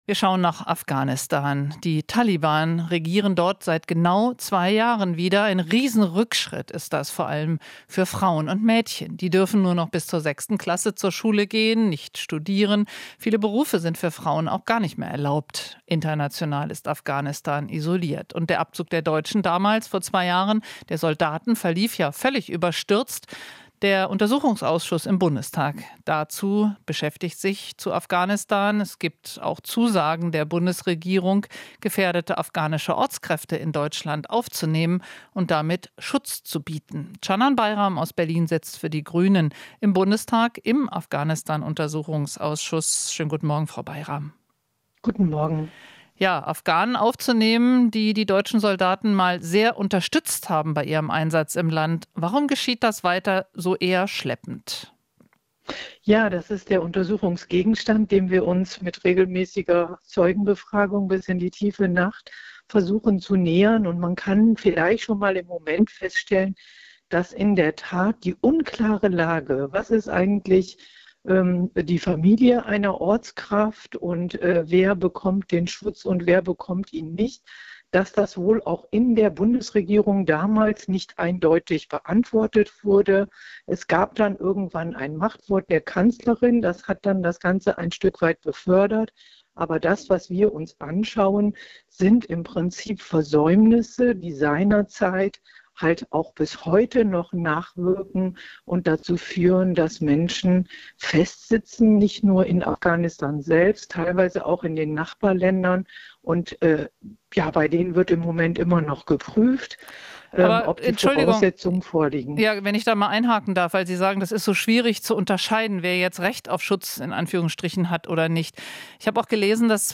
Interview - Bayram (Grüne): Noch viele afghanische Ortskräfte sitzen fest